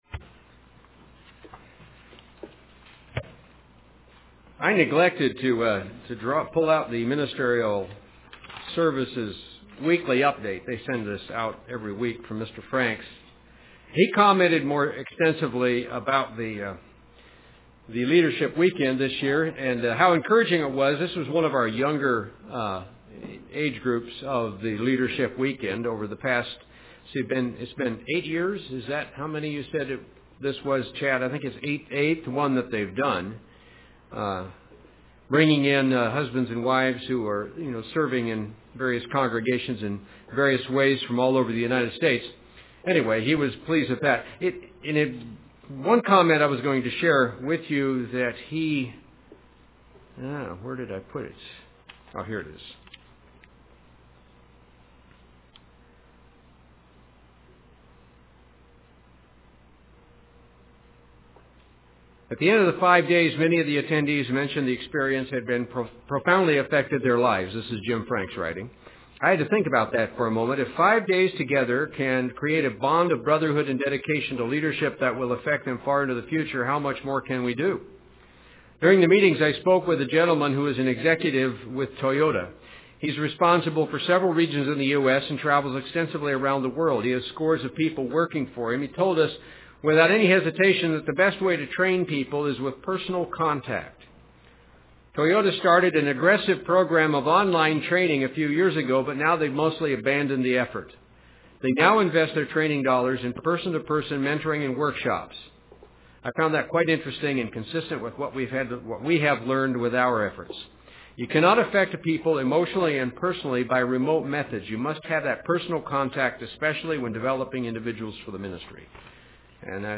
The Feast of Faith UCG Sermon Studying the bible?